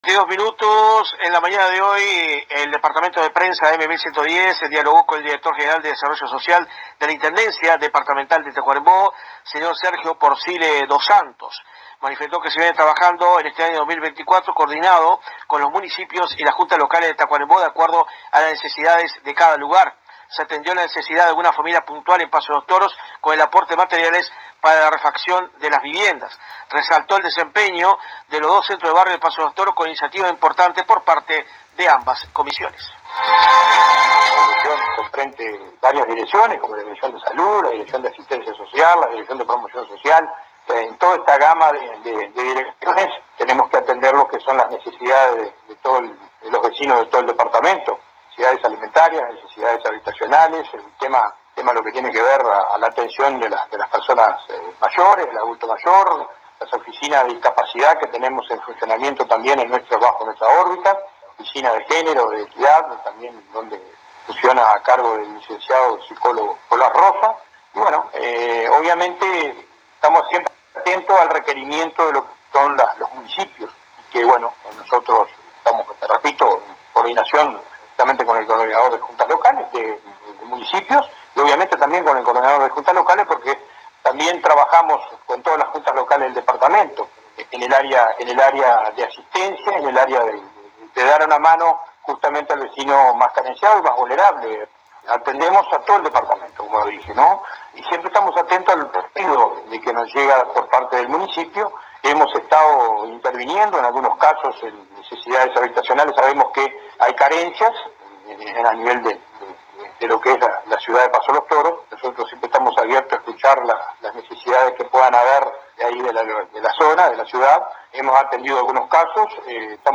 «Hemos trabajado muy bien con las comisiones de los dos Centros de Barrios que funcionan en Paso de los Toros: Charrúa y Plaza de Deportes, las cuales son muy activas, en cuanto a la atención de las necesidades de los vecinos siempre con el enlace entre el Municipio y la Intendencia Departamental», así lo dijo Sergio Porcile, Director General de Desarrollo Social del Ejecutivo tacuaremboense en contacto telefónico con la AM 1110 de nuestra ciudad.